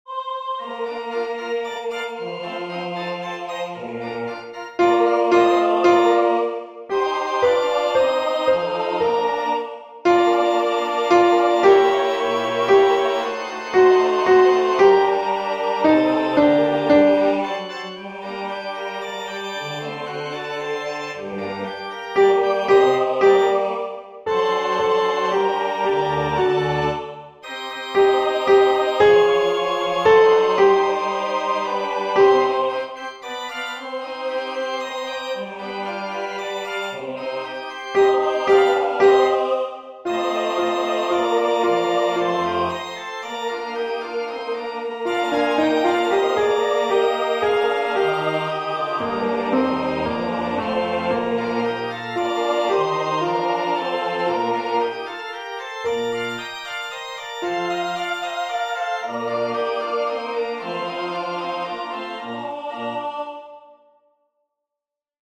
Number of voices: 4vv   Voicing: SATB
Genre: SacredMass
Instruments: Organ
HaydnMassConcAgnusAltoP.mp3